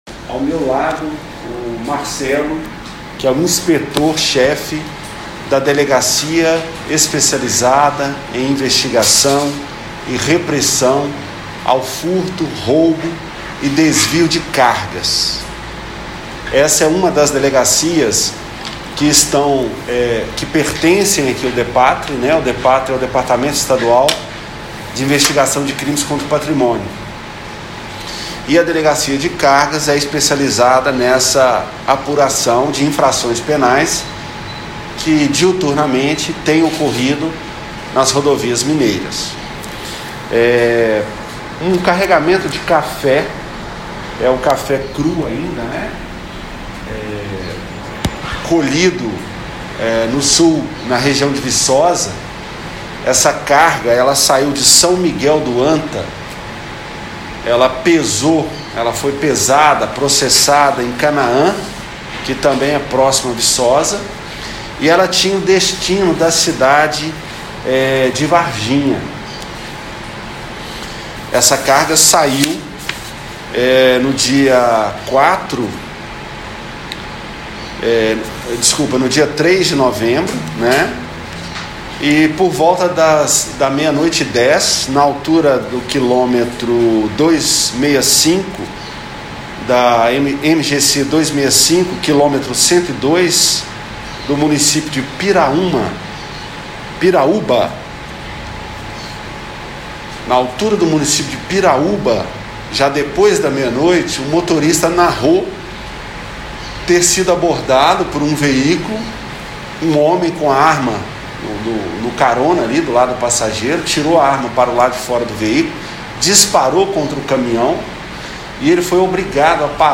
Coletiva-Cafe-Roubado.mp3